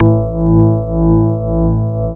orgTTE54018organ-A.wav